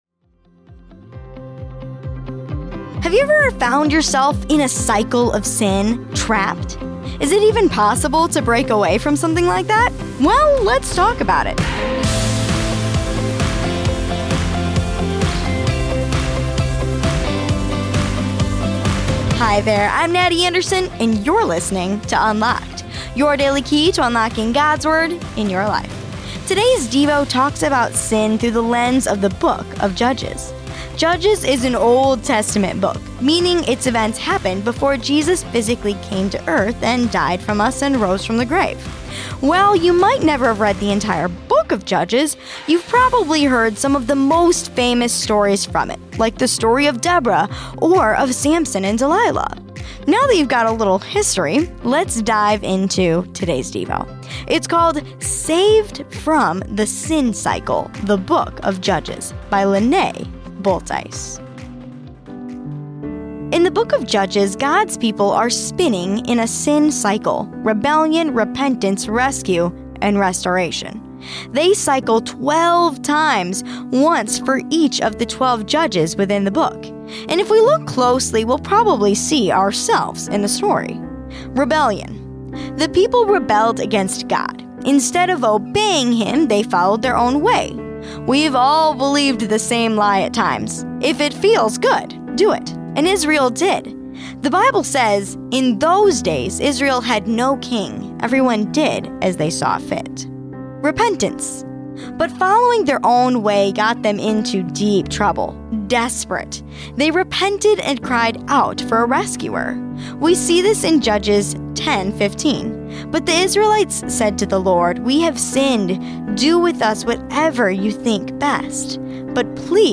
Play Rate Listened List Bookmark Get this podcast via API From The Podcast 2 Unlocked is a daily teen devotional, centered on God’s Word.